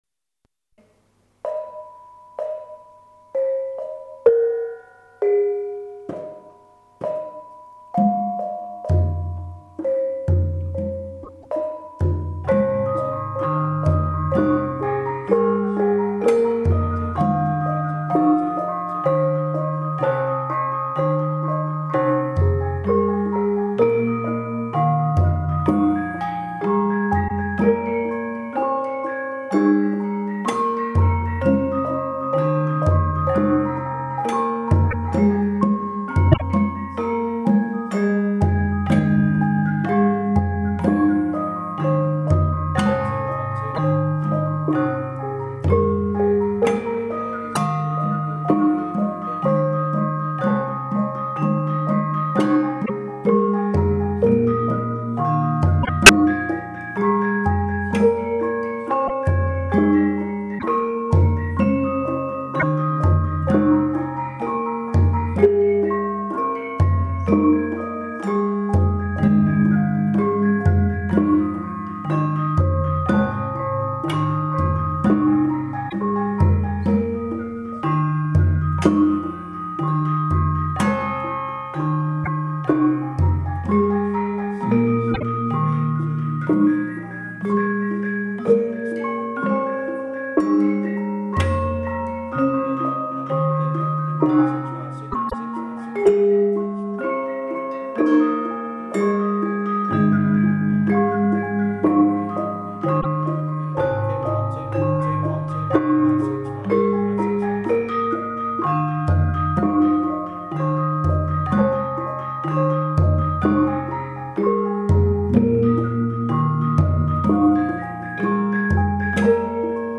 This is a slower, more refined piece.
There is a recording of the Durham group playing the piece here
You can hear all the elaborating instruments.
The bonangs are playing “mipil”.